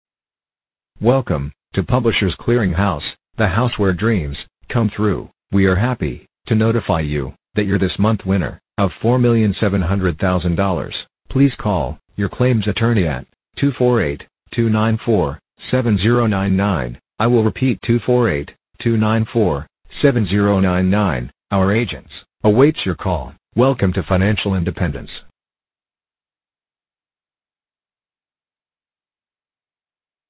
Voicemail
Robocall :arrow_down: